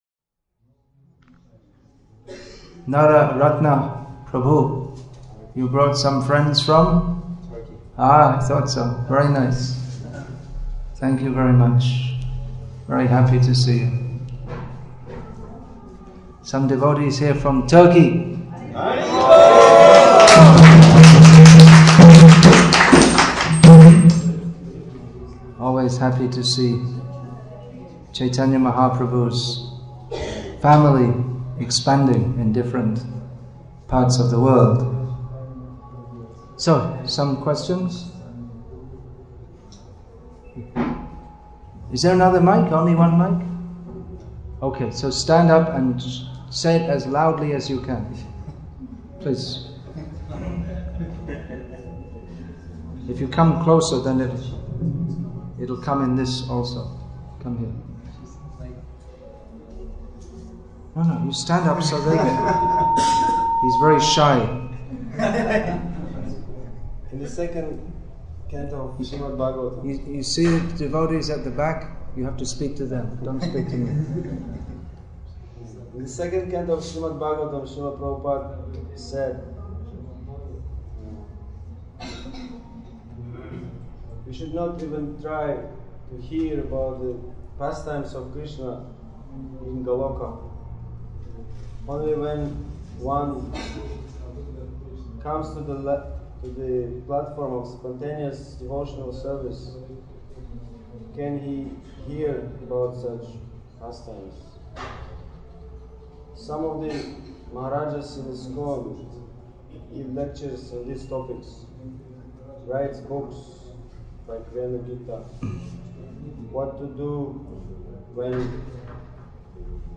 Athato Brahma-jijnasa – Questions and Answers – Day 3